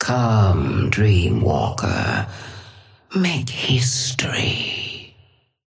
Sapphire Flame voice line - Come, dreamwalker. Make history.
Patron_female_ally_haze_start_01.mp3